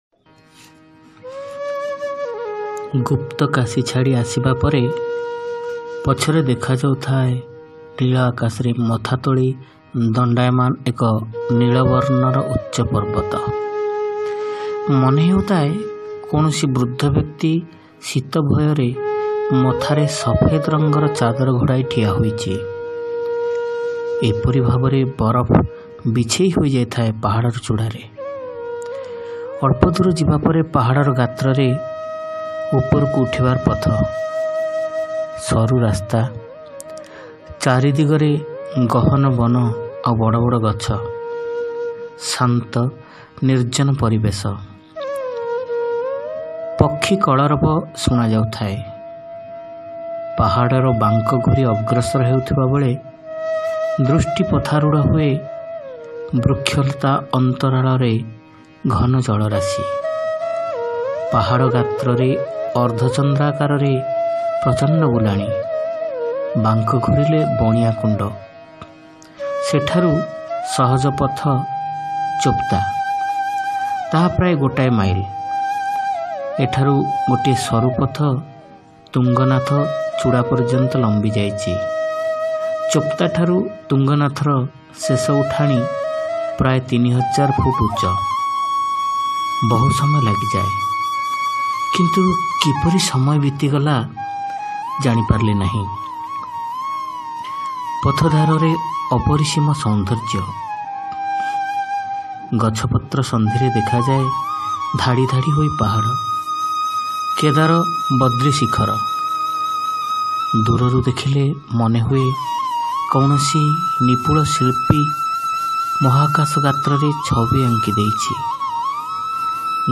Audio Story : Rahasyamaya Adhyatmika Jatra - Alokare Abhiseka